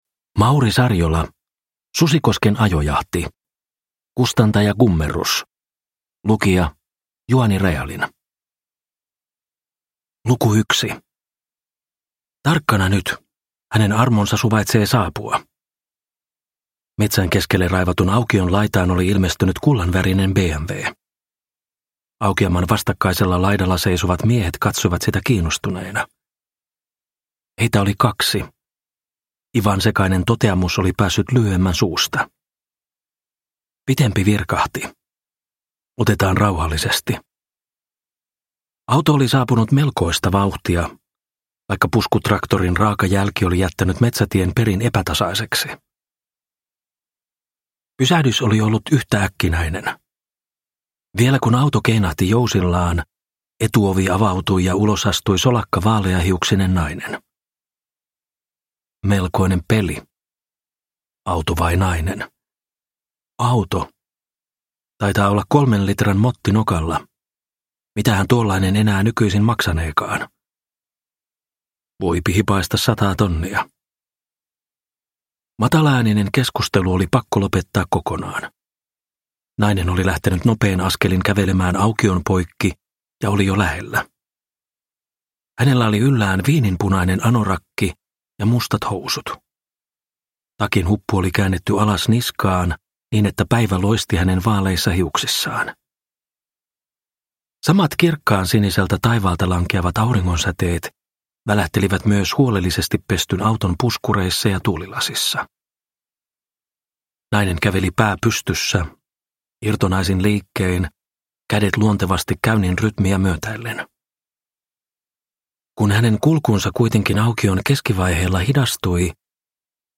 Susikosken ajojahti – Ljudbok – Laddas ner